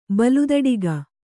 ♪ baludaḍiga